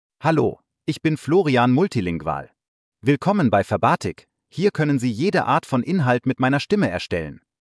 Florian Multilingual — Male German (Germany) AI Voice | TTS, Voice Cloning & Video | Verbatik AI
Florian Multilingual is a male AI voice for German (Germany).
Voice sample
Listen to Florian Multilingual's male German voice.
Male
Florian Multilingual delivers clear pronunciation with authentic Germany German intonation, making your content sound professionally produced.